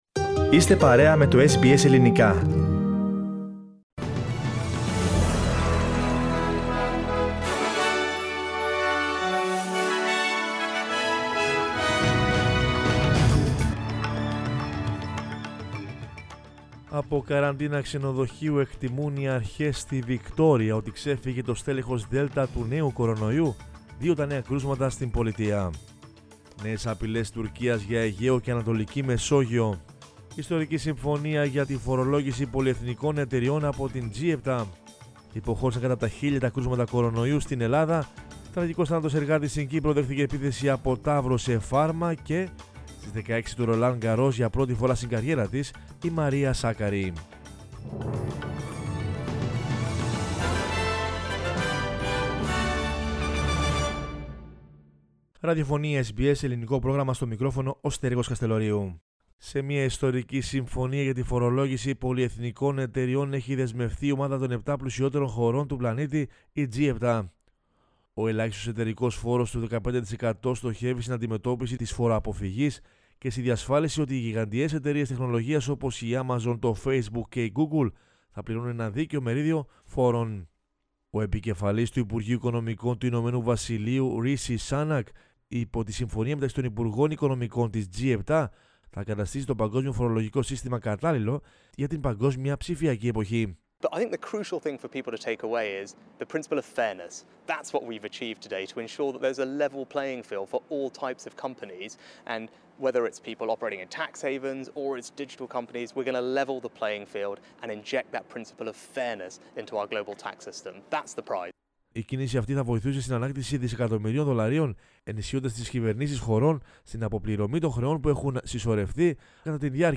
News in Greek from Australia, Greece, Cyprus and the world is the news bulletin of Sunday 6 June 2021.